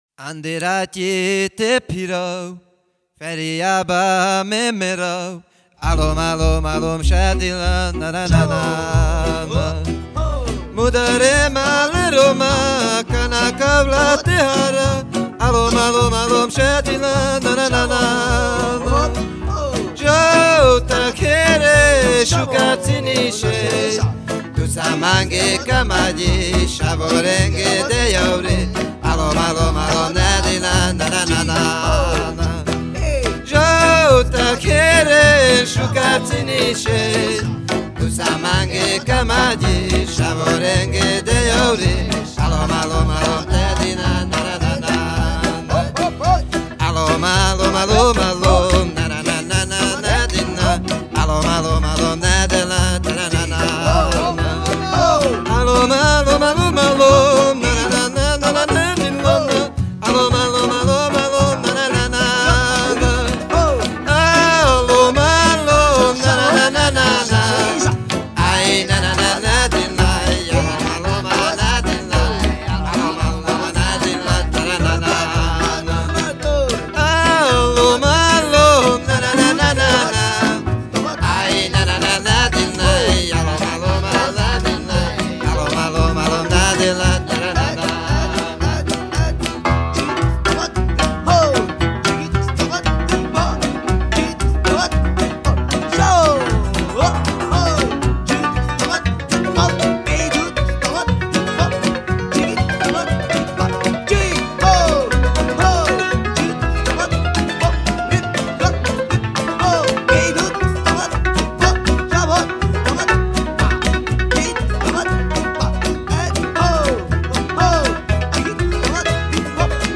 Музыка народов мира